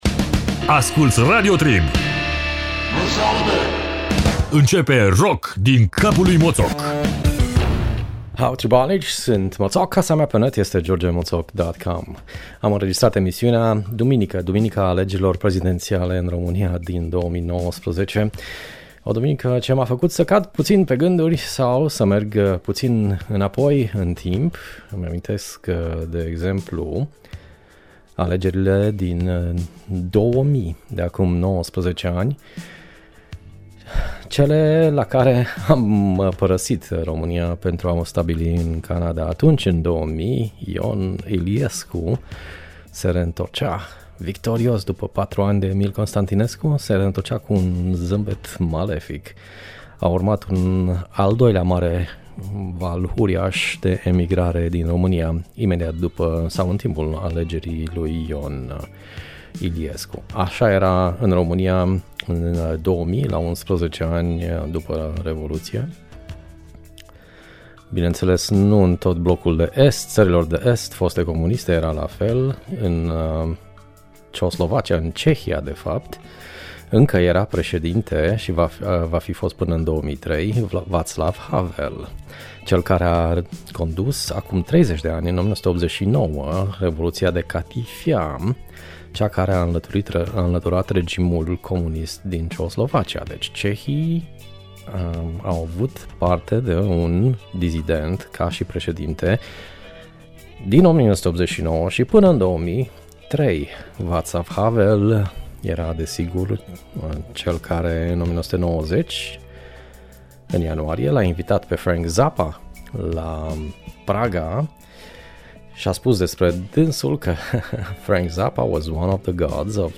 Cosmic jazz pentru fanii Alice Coltrane si Sun Ra.